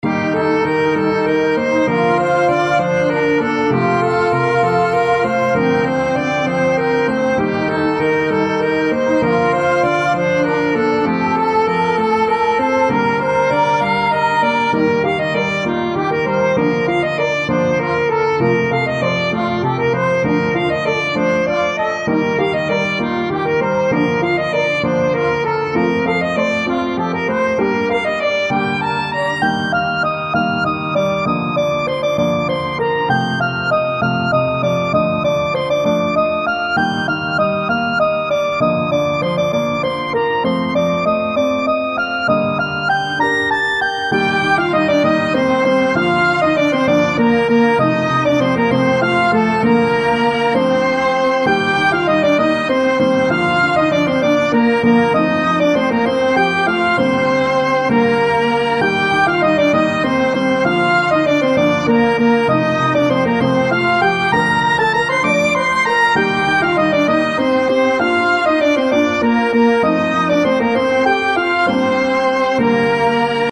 それぞれ１ループの音源です♪
イントロなし